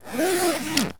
foley_zip_zipper_long_07.wav